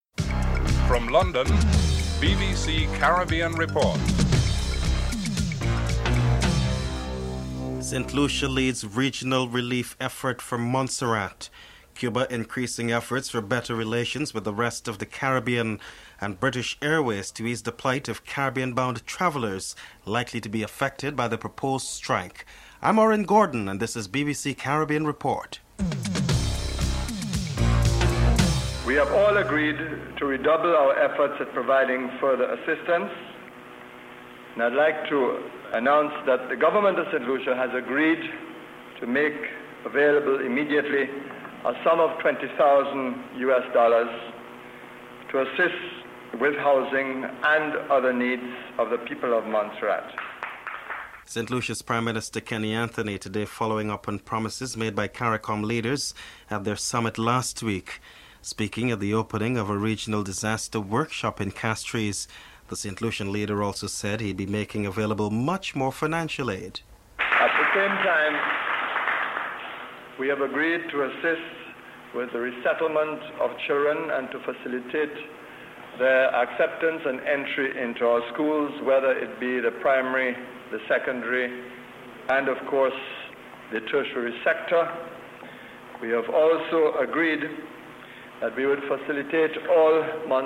1. Headlines (00:00-00:27)
Prime Minister Kenny Anthony is interviewed (00:28-01:48)